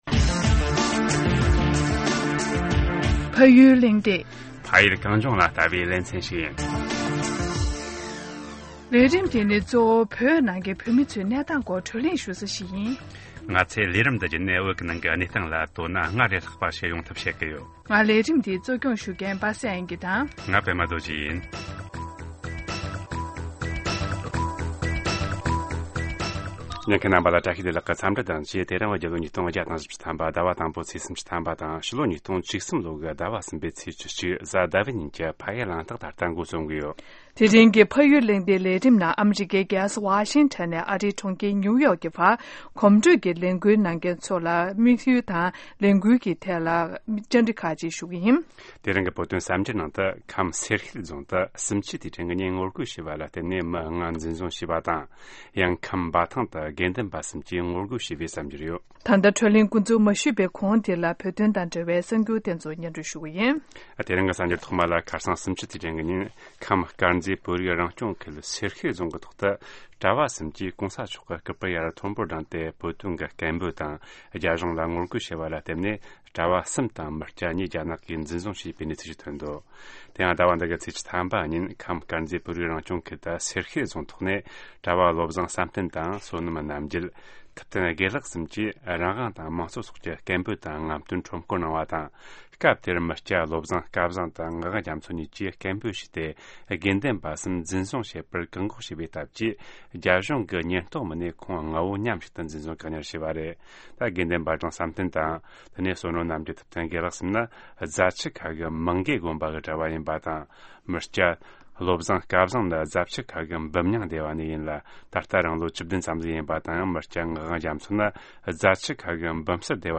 བགྲོ་གླེང་ཞུས་པ་ཞིག་གསན་གནང་རོགས་གནང་།